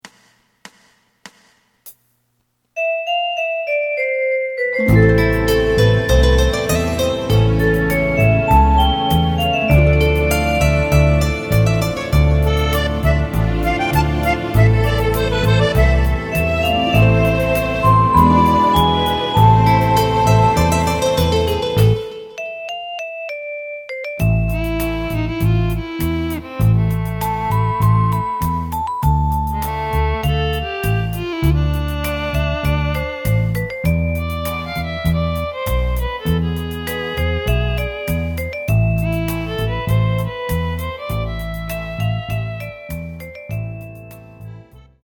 エレキバンド用カラオケCD製作・販売
すべての主旋律を１人で演奏するスタイルにアレンジしてみました。
●フルコーラス(デモ演奏) メロディライン＋伴奏が演奏されます。